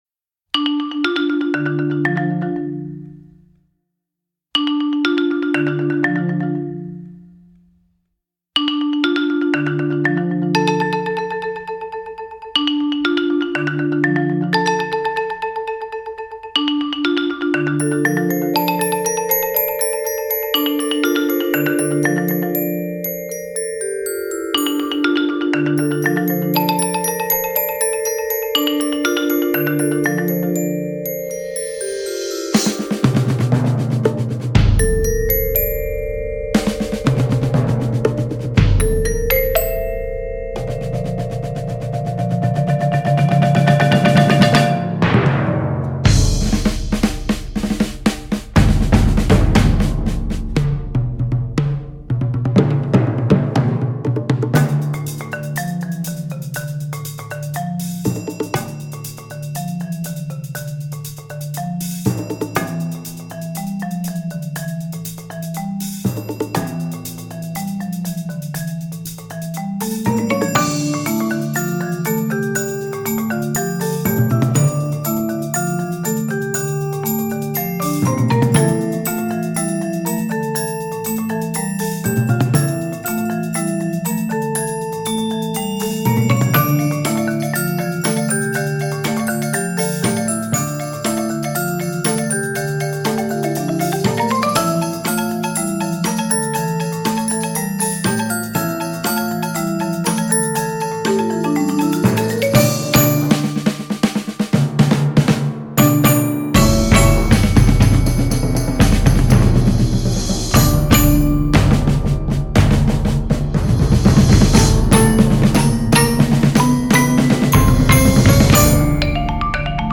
Voicing: 13-15 Percussion